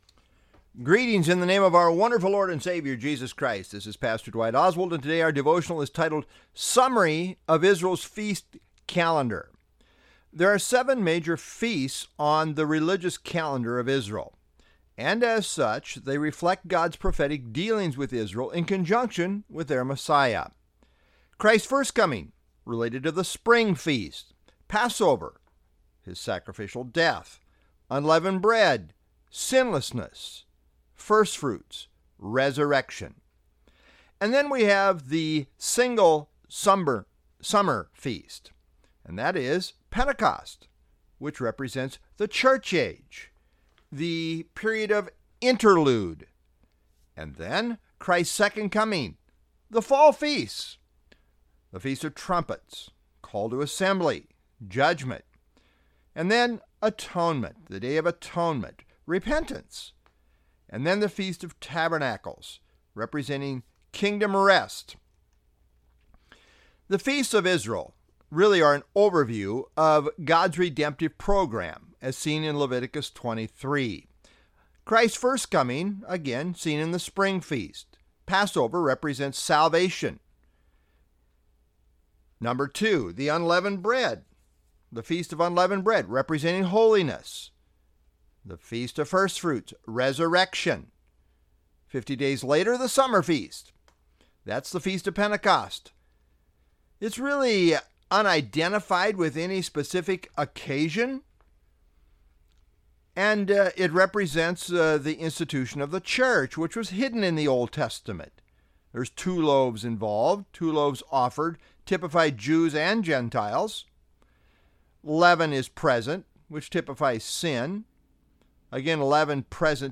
Sermons | Southview Bible Church
July 17, 2024 (Wednesday Evening)